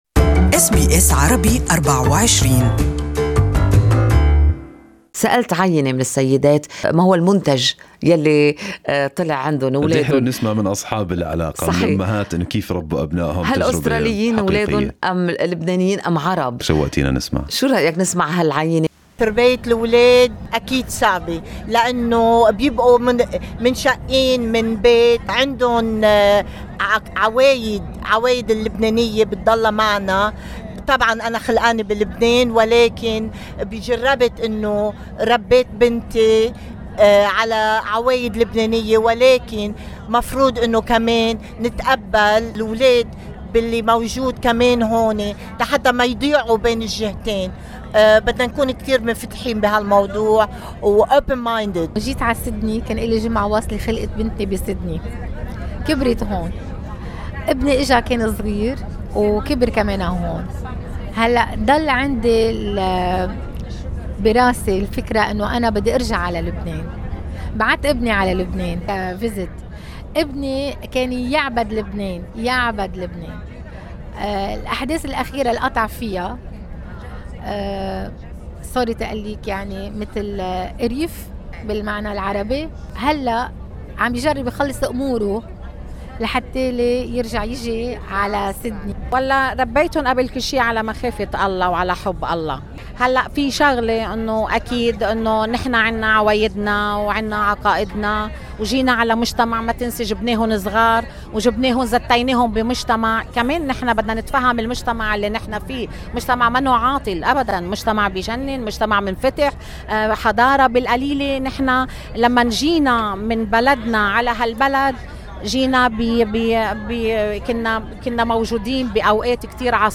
بإمكانكم الاستماع إلى آراء مستميعنا في التسجيل الصوتي المرفق بالصورة أعلاه.